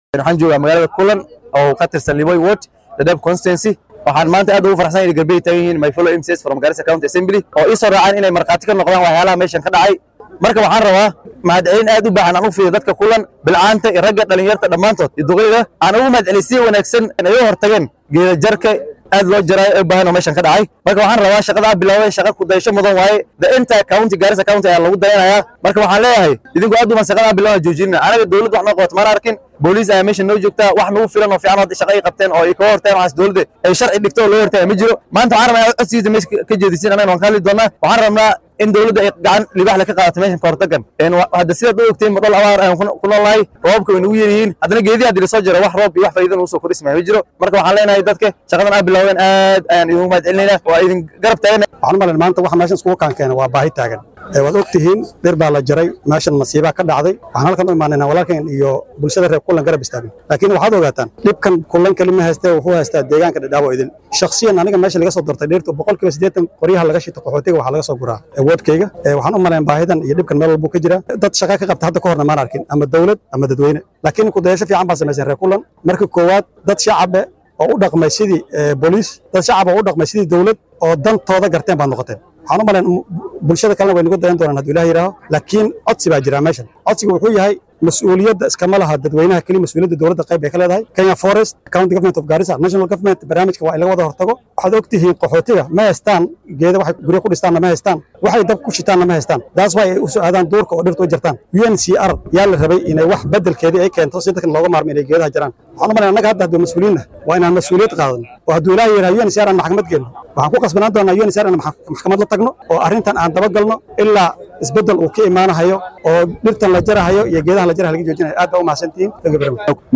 Qaar ka mid ah wakiillada laga soo doortay deegaanada hoose ee deegaan baarlamaaneedka Dadaab ee ismaamulka Garissa ayaa ka hadlay xaaluufin la sheegay in loo gaysto dhirta deegaankaasi. Mas’uuliyiintan oo ka hadlay magaalada Kullan oo ka tirsan degma waaxeedka hoose ee Liboi ayaa ku baaqay in la joojiyo dhirta la jarayo.